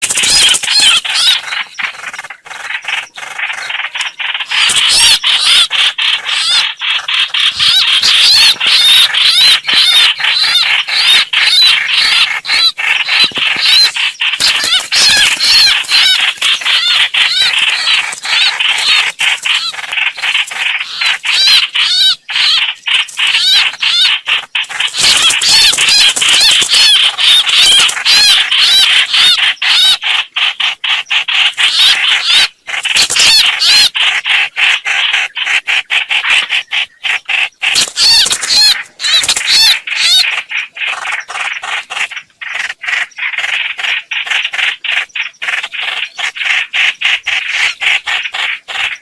Tiếng chim Chóc Quạch hót, kêu gọi bầy MP3